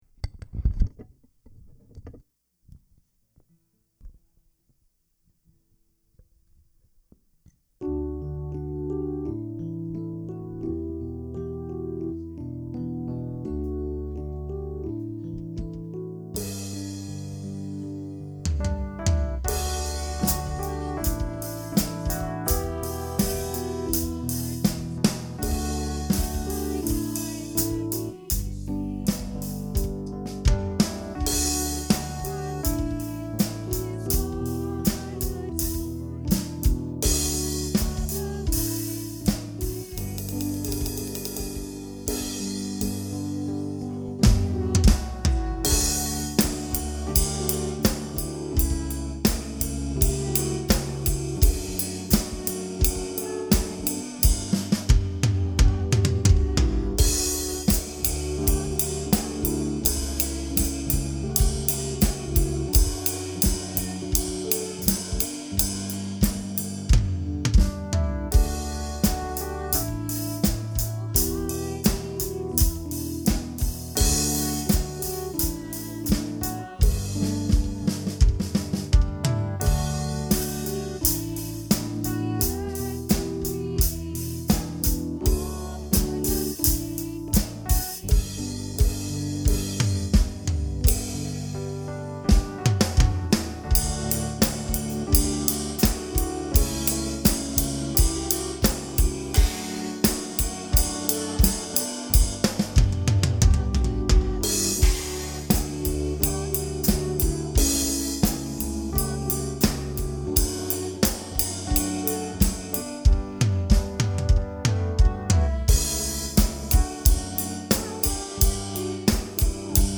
John 8:12-20 Service Type: Sunday Morning Worship “Jesus Said